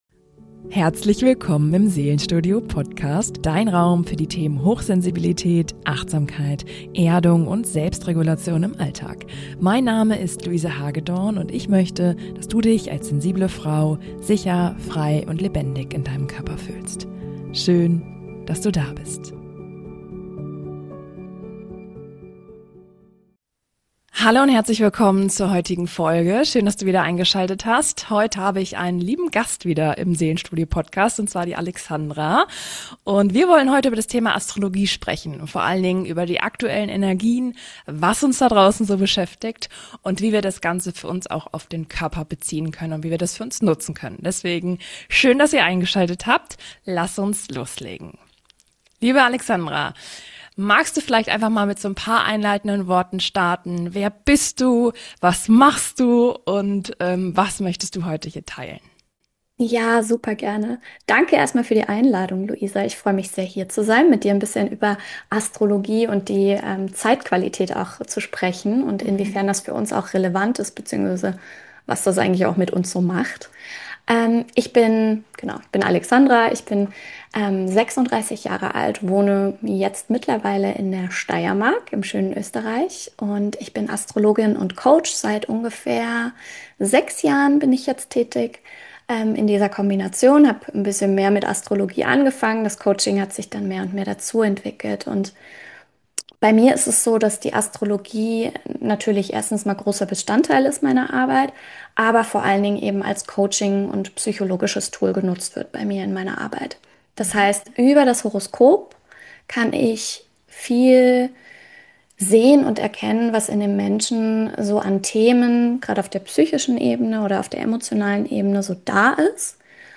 Astro-Interview